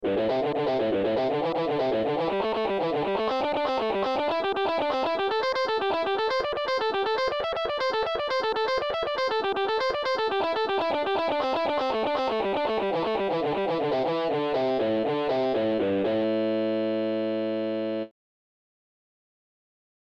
Blues Exercises > Pentatonic speed lick
Pentatonic+speed+lick.mp3